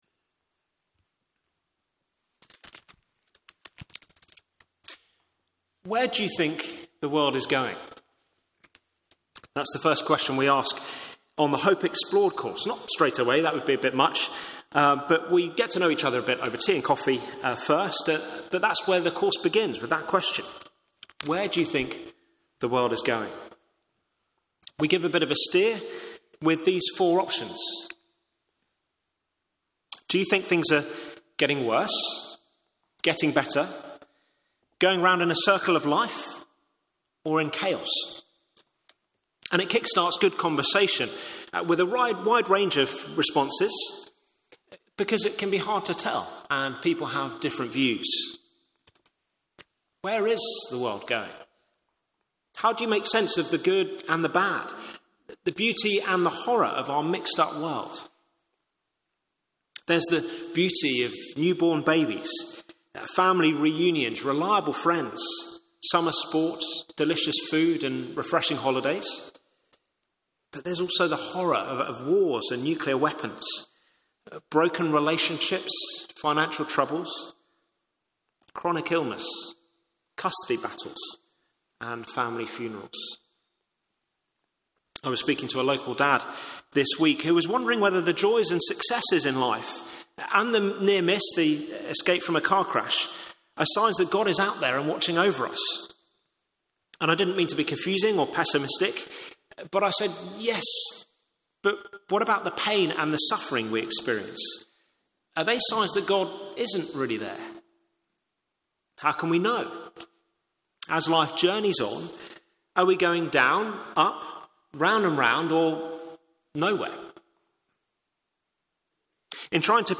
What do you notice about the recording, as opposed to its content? Media for Morning Service on Sun 29th Jun 2025 10:30 Speaker